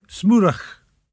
[smOO-rach]